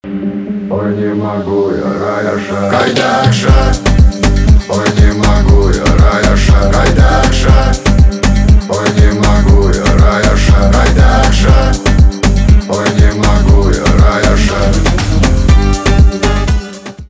русский рэп
битовые , басы , кайфовые